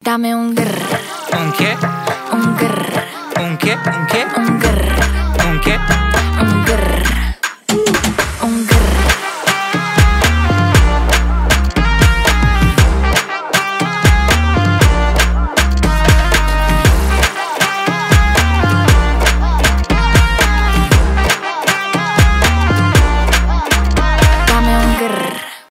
rnb
танцевальные